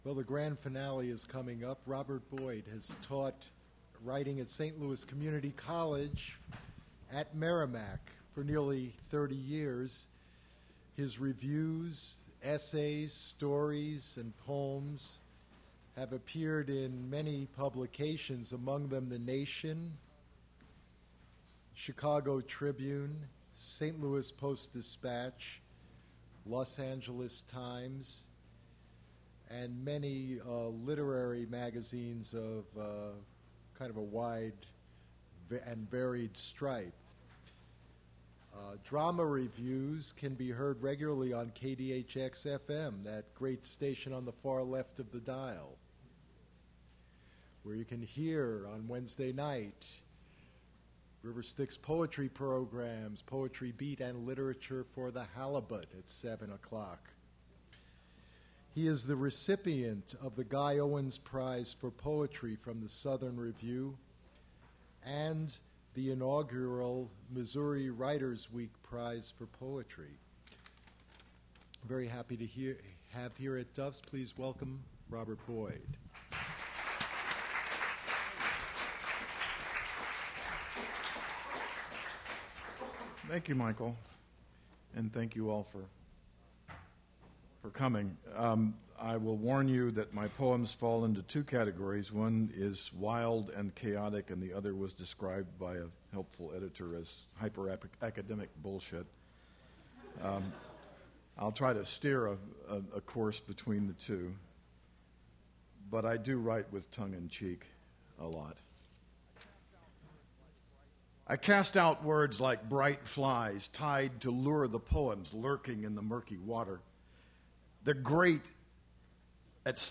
Poetry reading
generated from original audio cassette